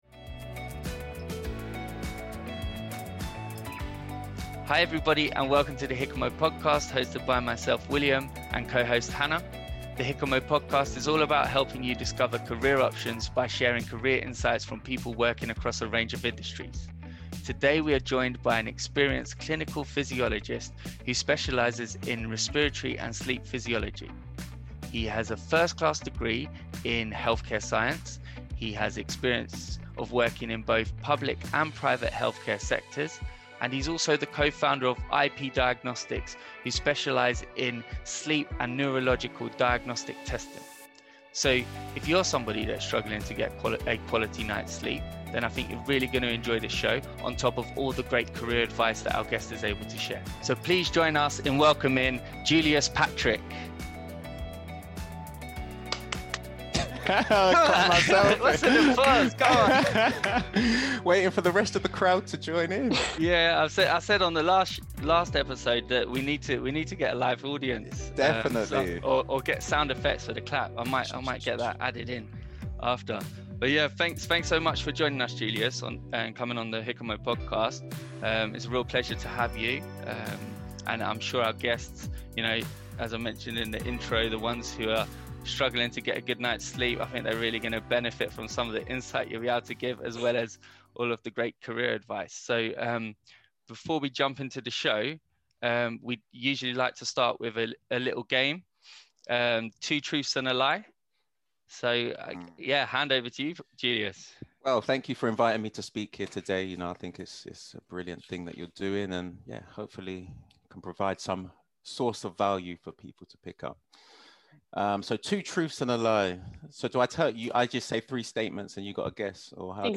We are joined by an experienced clinical physiologist who specialises in the field of respiratory and sleep physiology.